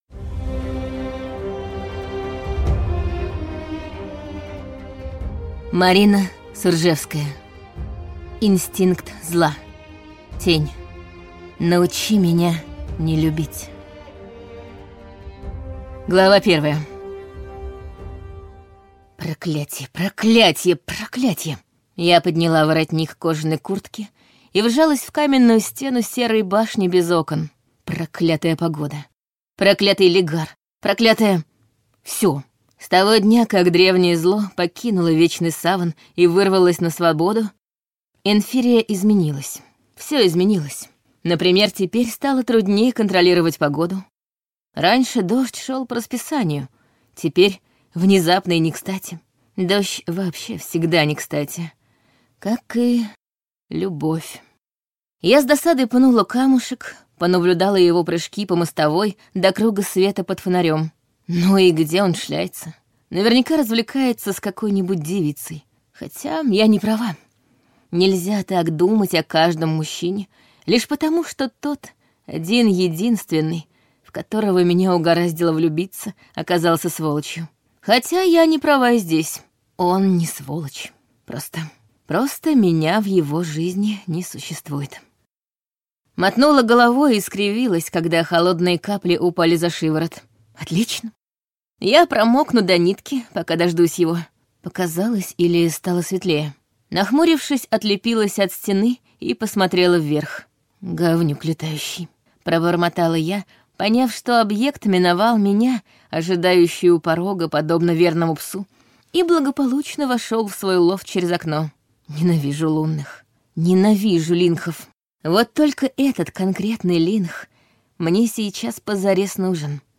Аудиокнига Инстинкт зла. Тень | Библиотека аудиокниг